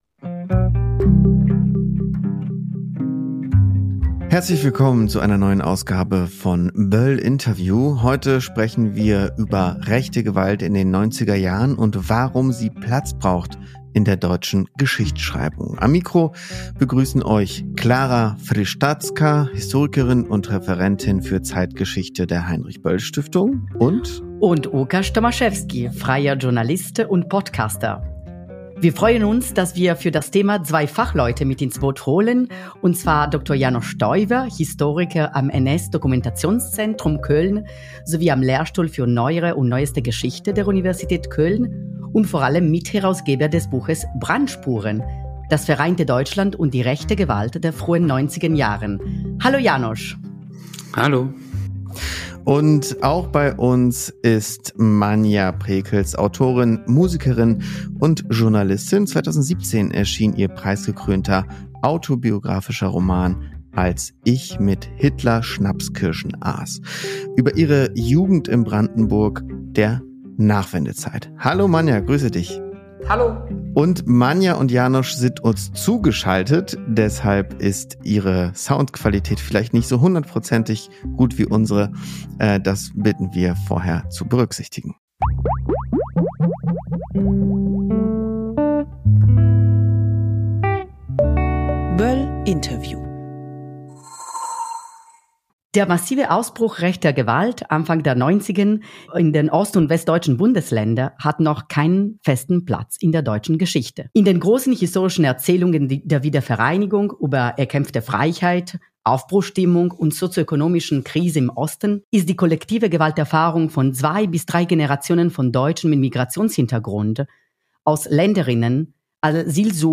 Was muss aufgearbeitet werden und wie kann die Opferperspektive sichtbar gemacht werden? Ein Gespräch mit einer Autorin und einem Historiker.